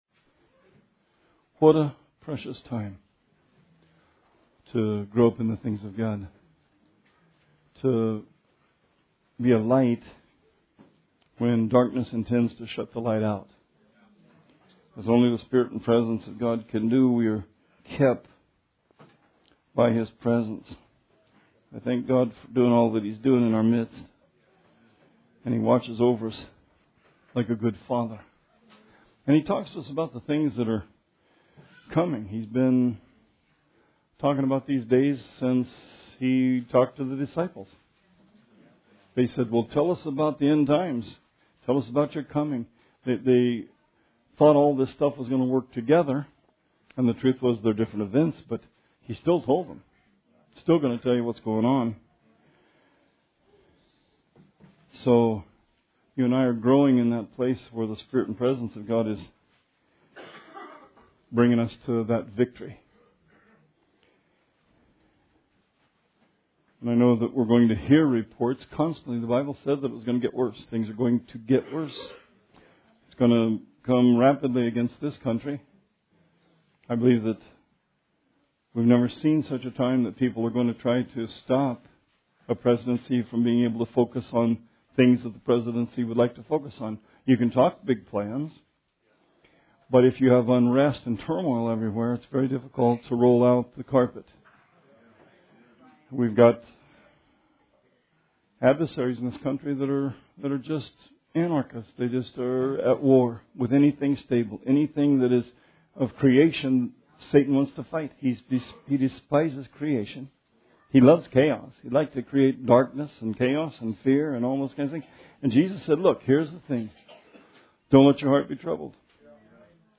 Sermon 1/8/17